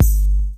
JJKicks (8).wav